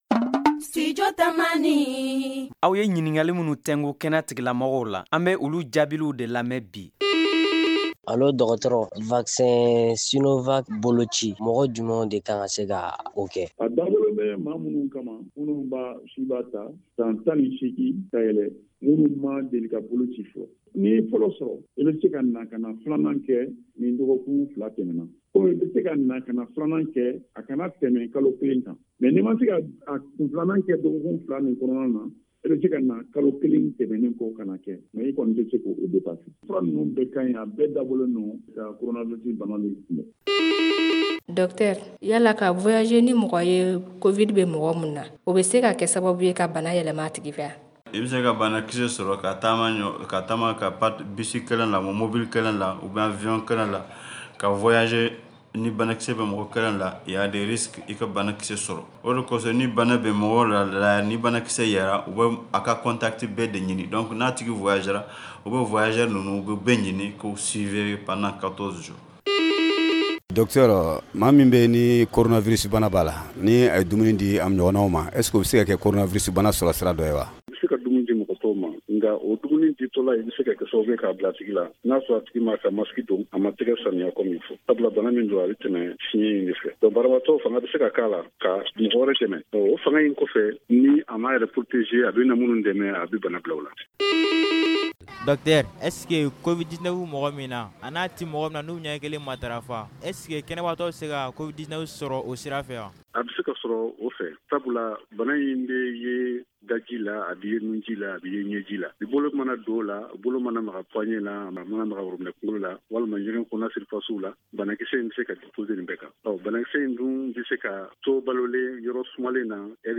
Ce numéro porte sur une série de questions posées par des auditeurs. Les réponses sont données par des spécialistes de la santé à ces préoccupations.